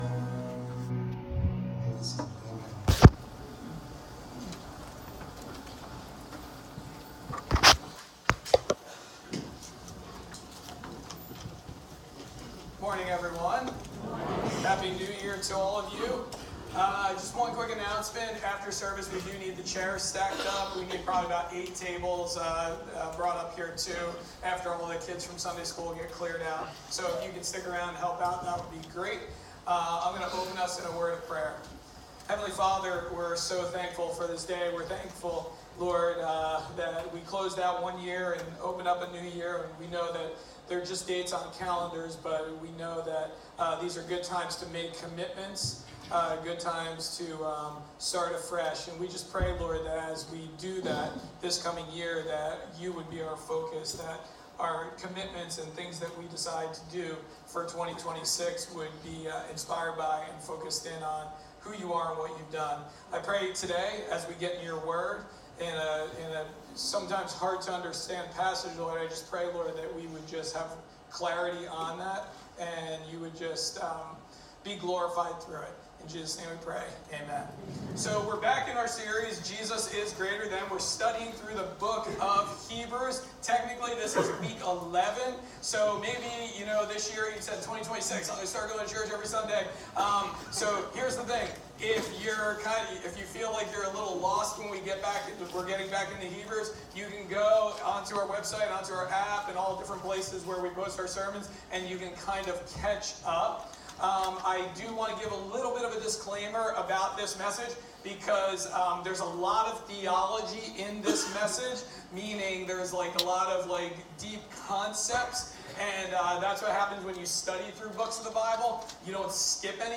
Sorry, we had technical difficulties, so the audio is not that good, and we have no video.